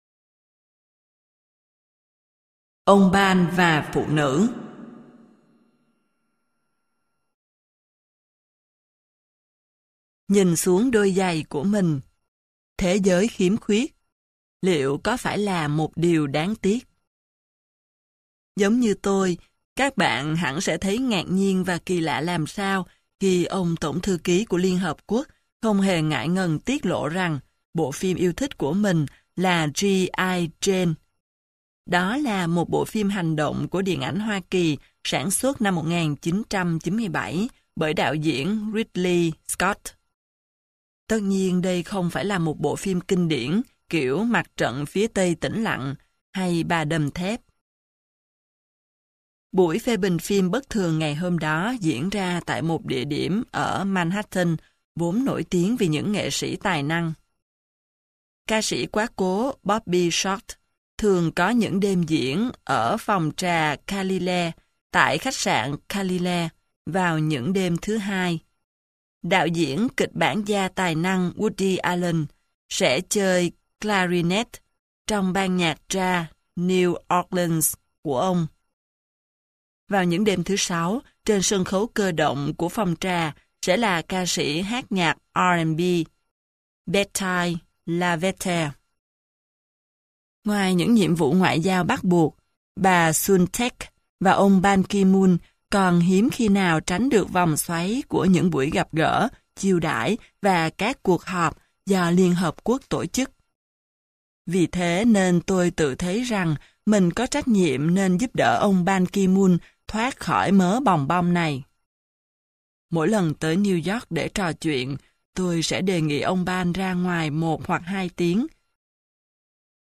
Sách nói Đối Thoại Với Banki moon - Tom Plate - Sách Nói Online Hay